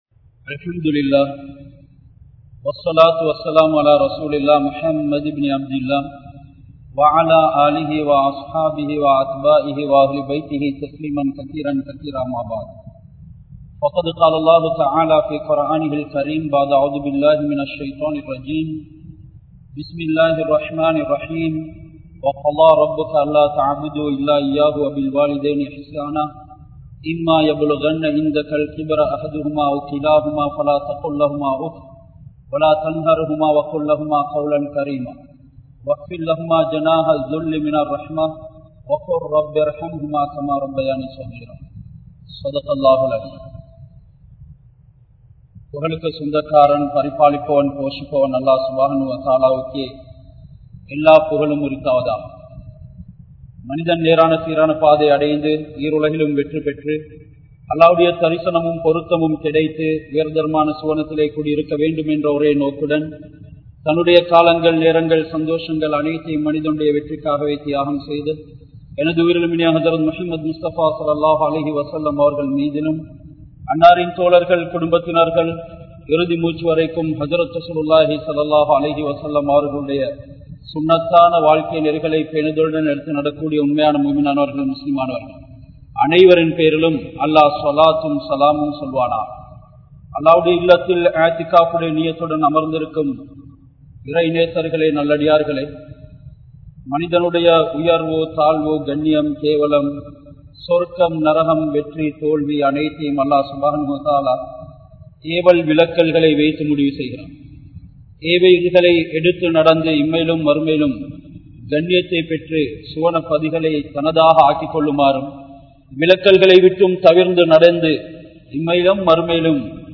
Petroarhalai Virattum Pillaihal (பெற்றோர்களை விரட்டும் பிள்ளைகள்) | Audio Bayans | All Ceylon Muslim Youth Community | Addalaichenai
Colombo 12, Aluthkade, Muhiyadeen Jumua Masjidh